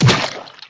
impactsplat03.mp3